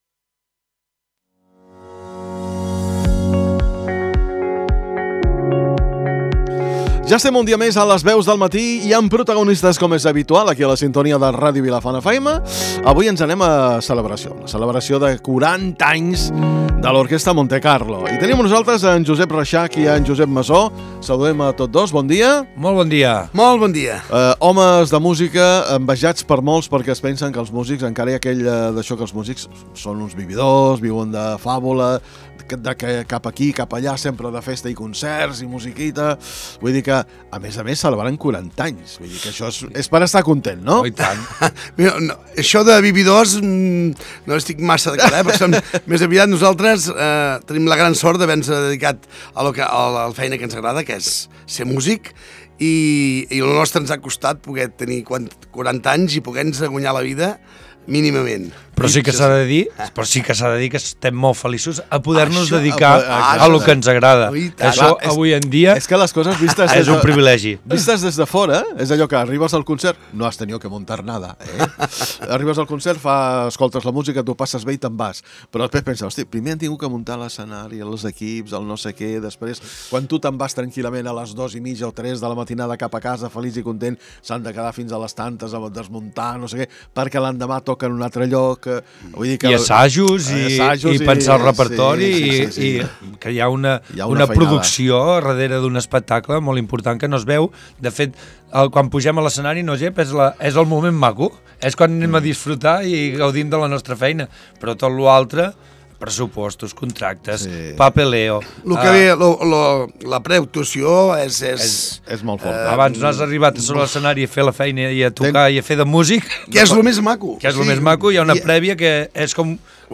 LVMD - ENTREVISTA - ORQUESTRA MONTECARLO 3 DESEMBRE 24.mp3